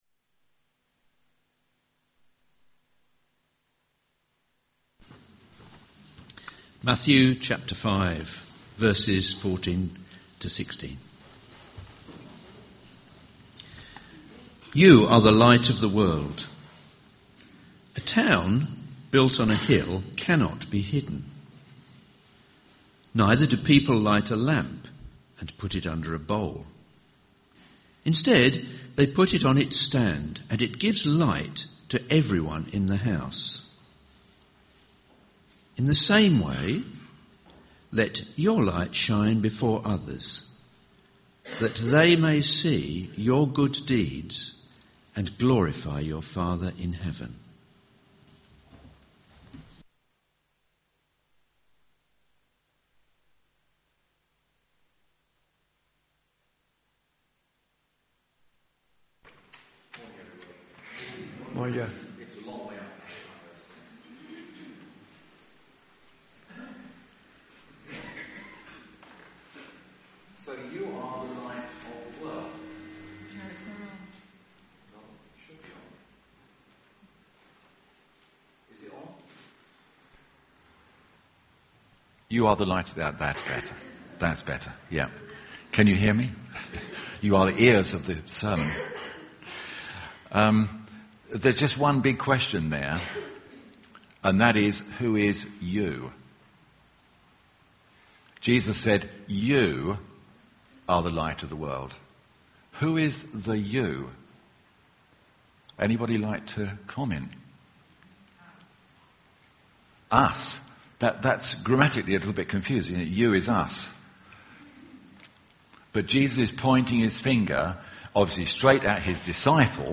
A message from the series "Practical Parables."